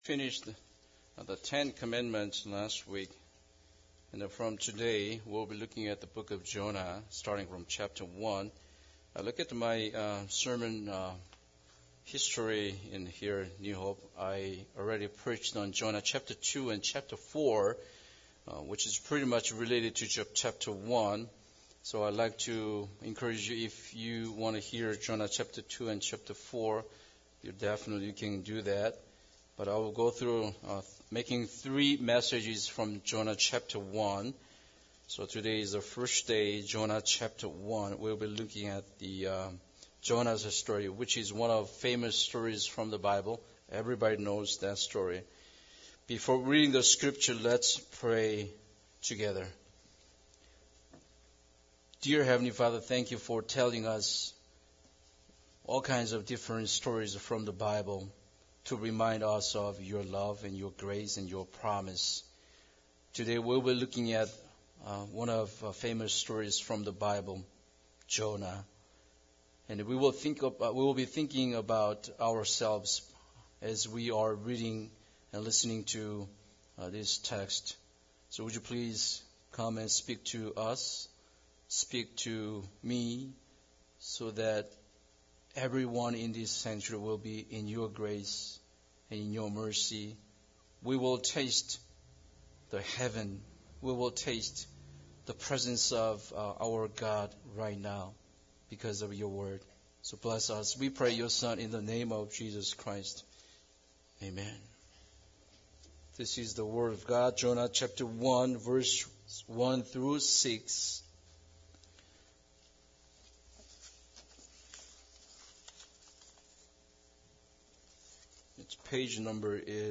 Jonah 1:1-6 Service Type: Sunday Service Bible Text